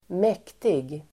Uttal: [²m'ek:tig]